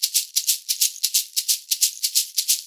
Index of /90_sSampleCDs/USB Soundscan vol.36 - Percussion Loops [AKAI] 1CD/Partition A/10-90SHAKERS